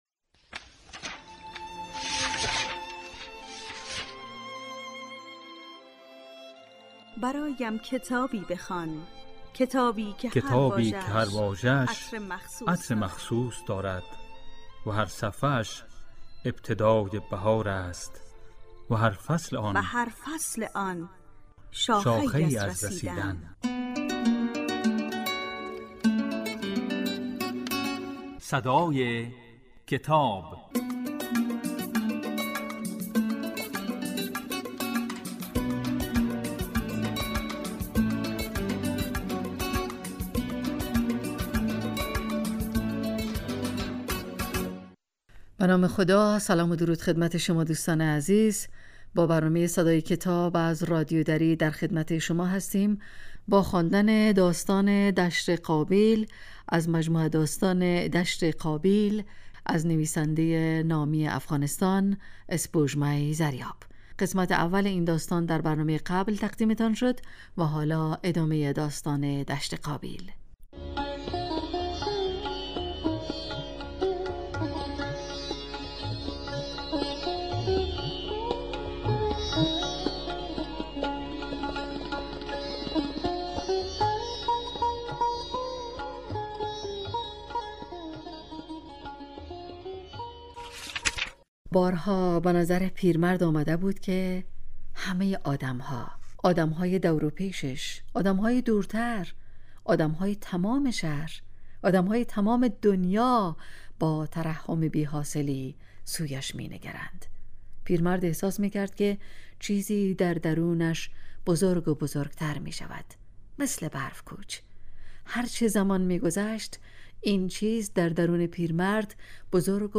در این برنامه، به دنیای کتاب‌ها گام می‌گذاریم و آثار ارزشمند را می‌خوانیم.